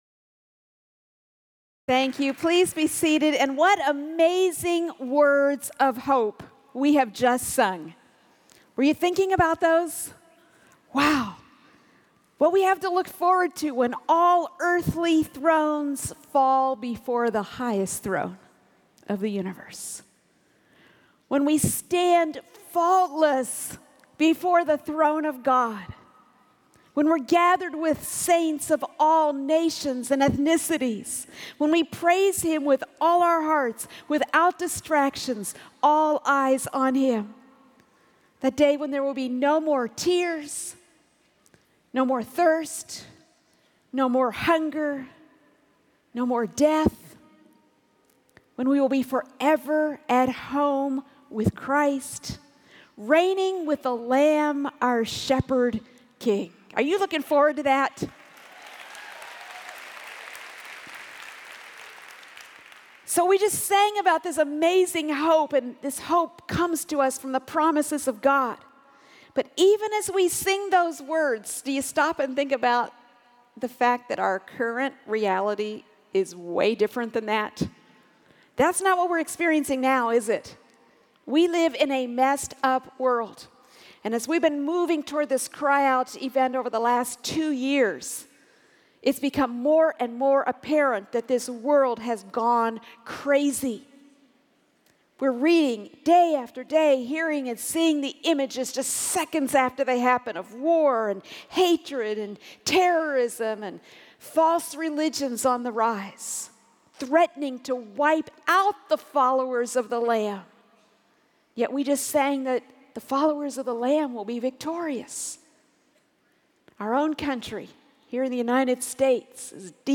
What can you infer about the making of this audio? Judgment and Hope—A Message for Our Time | True Woman '16 | Events | Revive Our Hearts